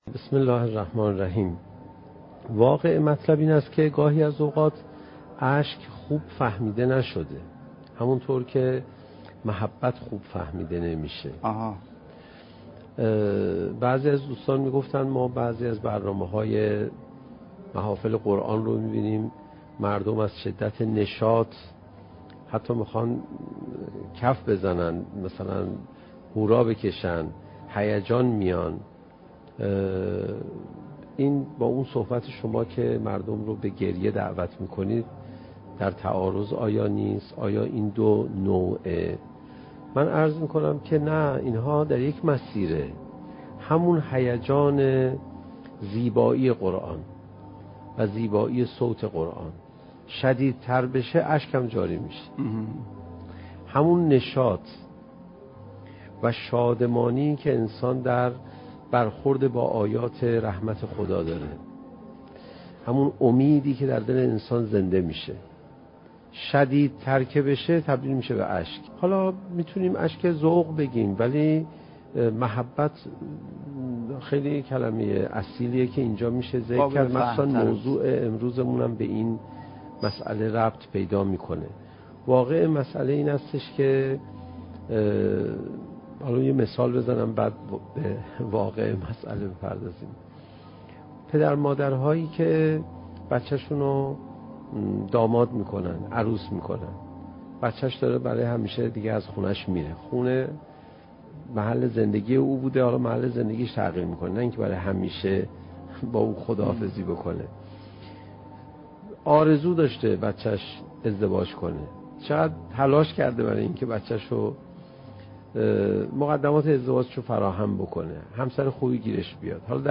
سخنرانی حجت الاسلام علیرضا پناهیان با موضوع "چگونه بهتر قرآن بخوانیم؟"؛ جلسه چهارم: "کتاب محبت خدا"